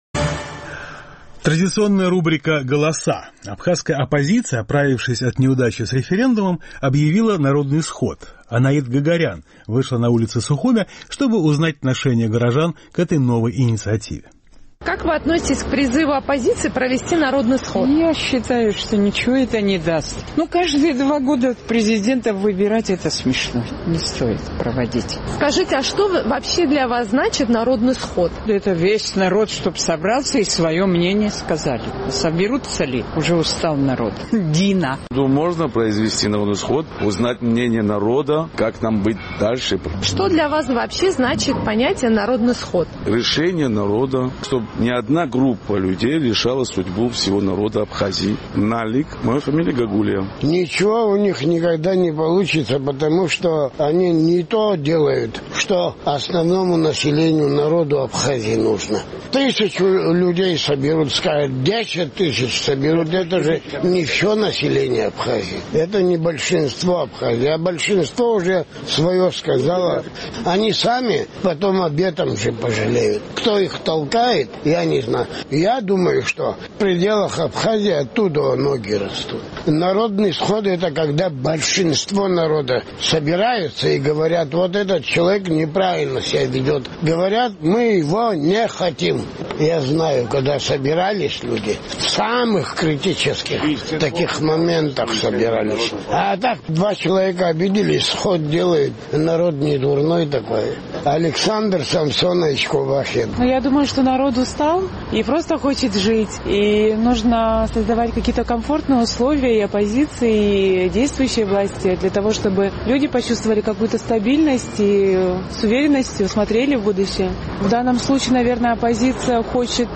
Абхазская оппозиция, пережив неудачу с референдумом, позвала людей на народный сход. Наш сухумский корреспондент поинтересовалась отношением горожан к этой идее.